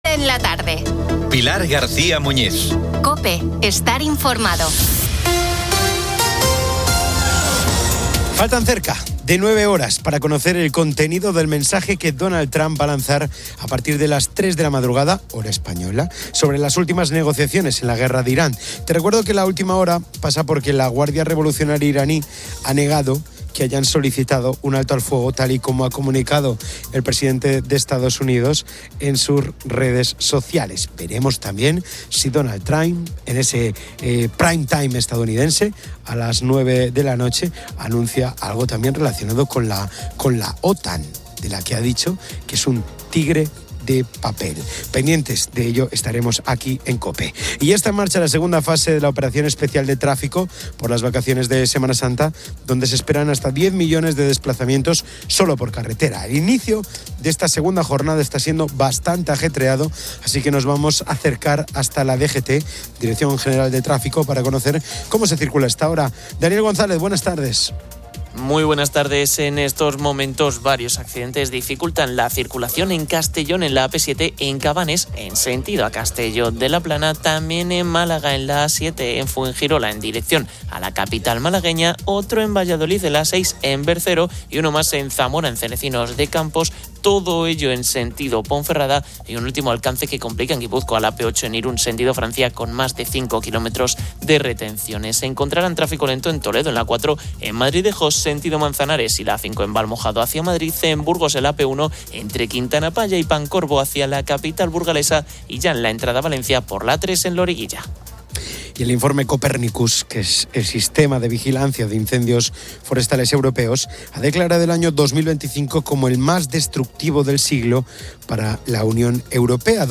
La programación de Cope se centra en la vibrante Semana Santa en España, mostrando la salida de más de 200 procesiones. Desde Madrid, se cubre en vivo la procesión del Cristo de las Tres Caídas, con testimonios emocionantes de costaleros y cofrades sobre su fe y la preparación.